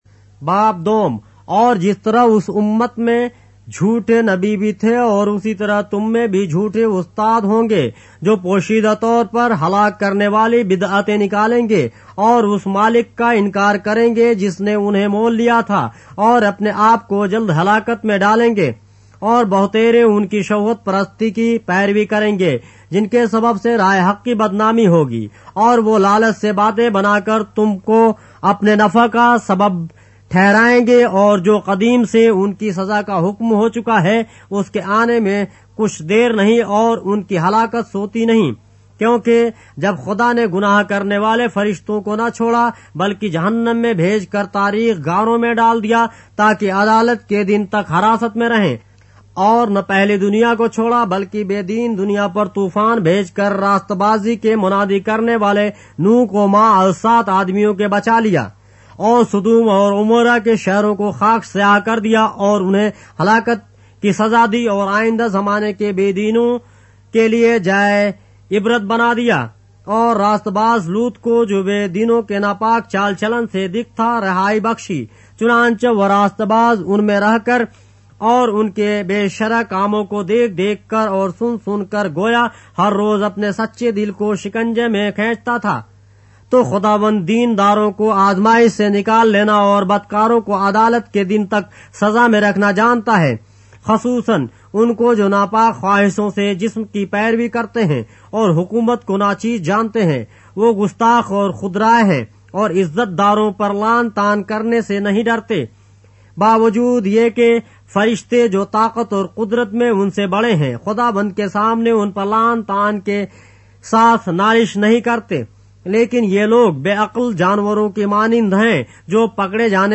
اردو بائبل کے باب - آڈیو روایت کے ساتھ - 2 Peter, chapter 2 of the Holy Bible in Urdu